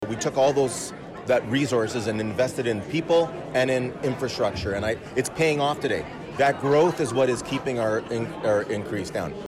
That was the theme of Wednesday’s State of the City address by the mayor of Belleville Mitch Panciuk.
Panciuk was speaking to members of the Belleville Chamber of Commerce at The Grand in the city’s west end.